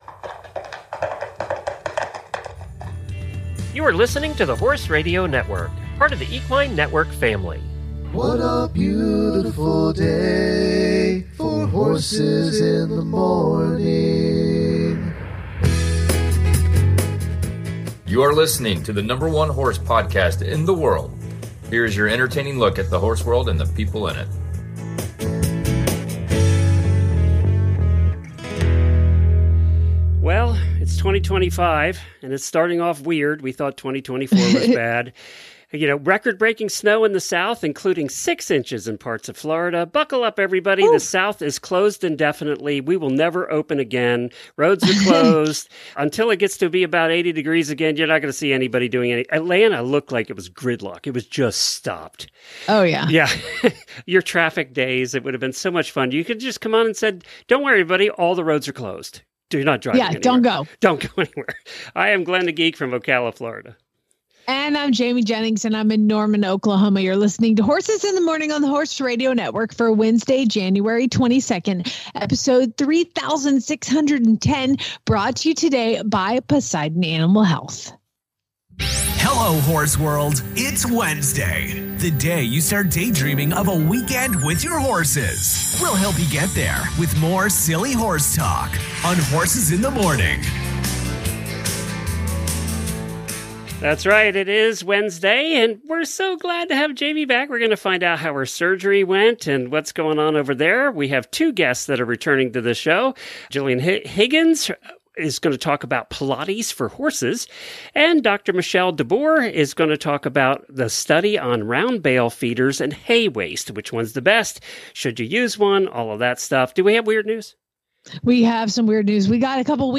and we have two guests returning to the show.